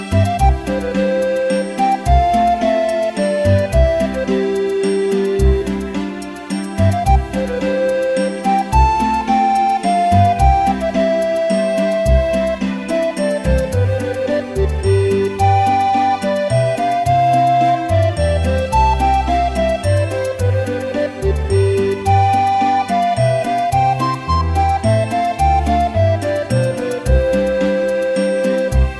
• Качество: 128, Stereo
без слов
легкие
сказочные
весеннее настроение